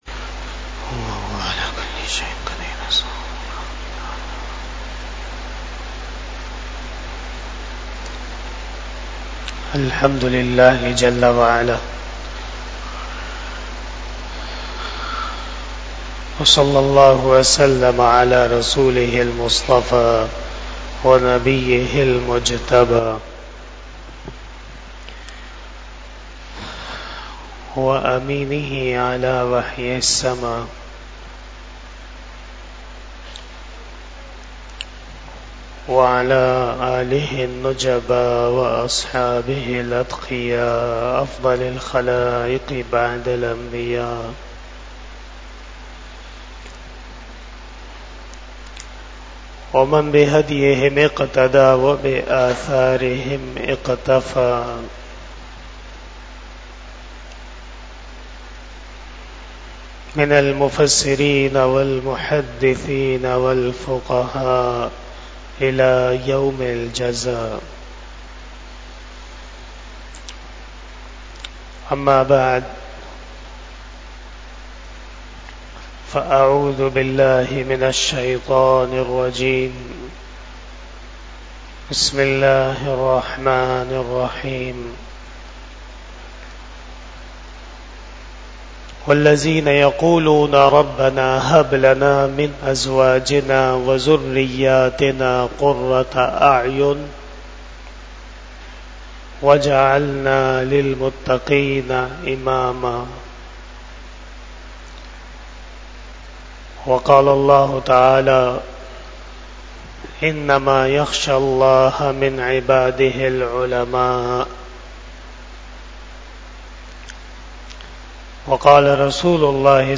010 Islahi Bayan After Maghrib Bayan 18 August 2024 (13 Safar 1446 HJ) Sunday